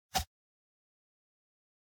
snd_explo2.ogg